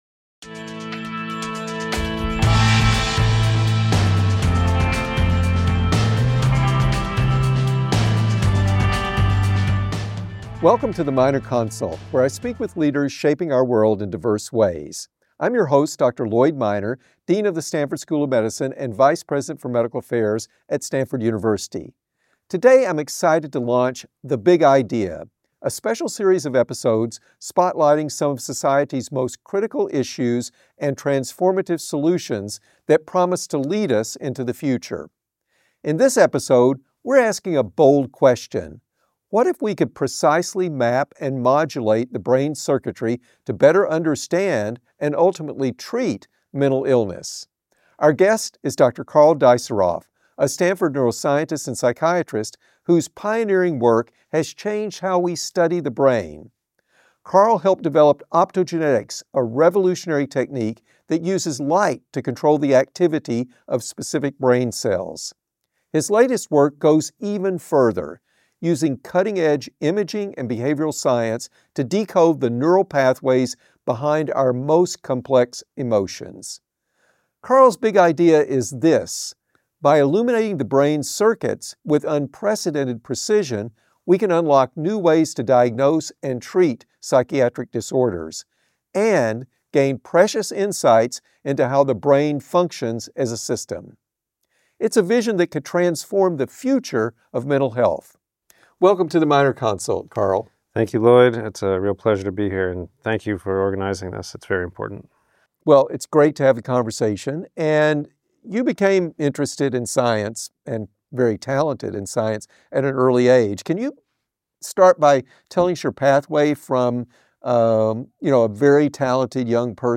In an episode of his Big Idea series, Dean Lloyd Minor welcomes Stanford neuroscientist Karl Deisseroth, MD, PhD, for a conversation about how his groundbreaking research is reshaping our understanding of the brain and the experiences that define us as human.